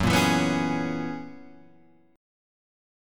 F# 11th